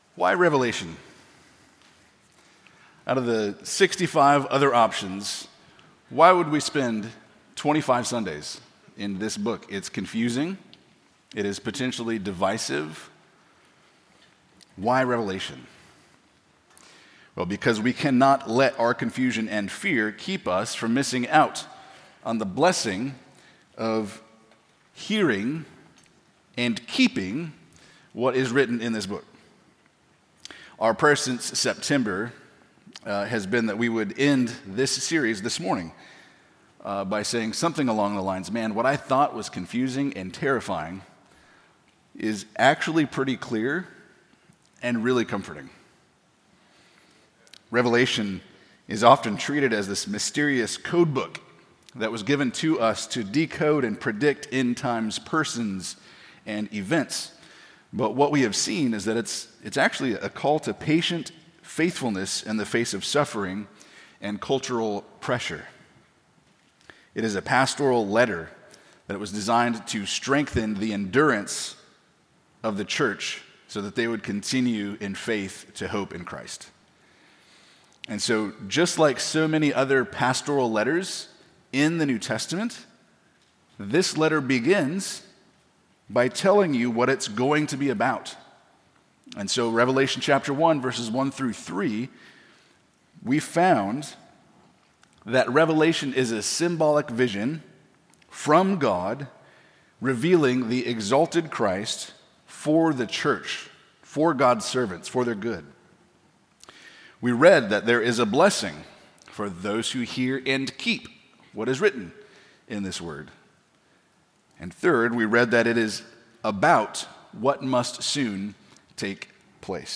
Expositional Preaching from Trinity Bible Church in Phoenix, Arizona